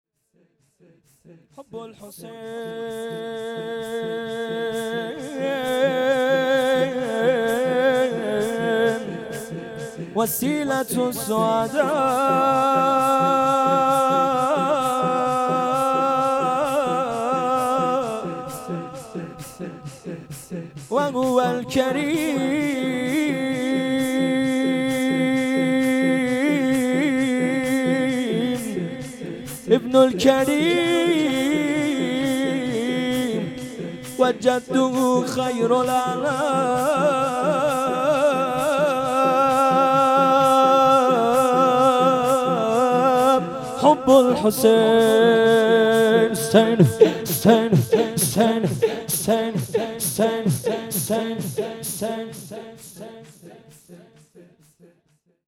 هیات انصارالمهدی (عج) بندرامام خمینی (ره)
ذکر
شب هفتم محرم 1398